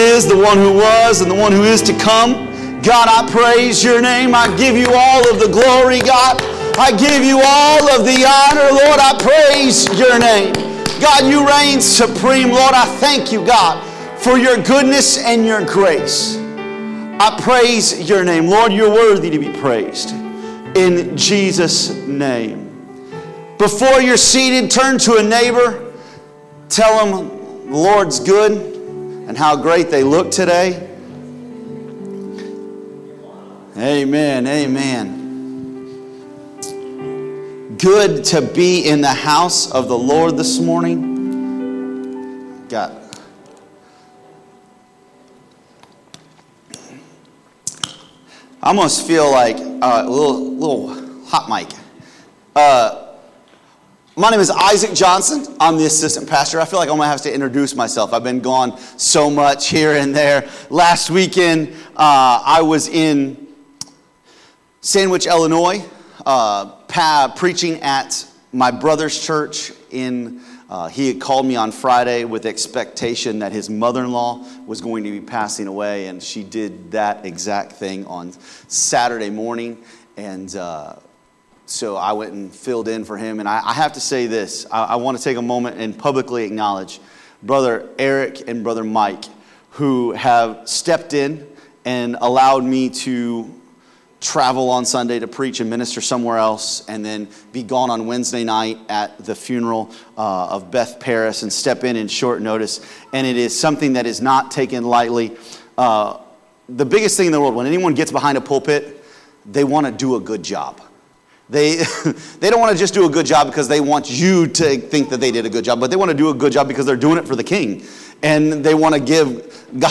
Sermons | Elkhart Life Church
Sunday Service - Part 14